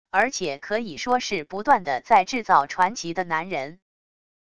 而且可以说是不断的在制造传奇的男人wav音频生成系统WAV Audio Player